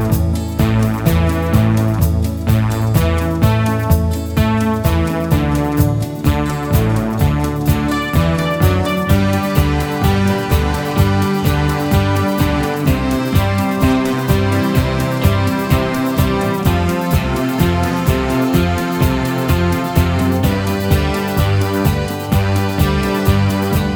Minus Guitars Pop (1970s) 3:21 Buy £1.50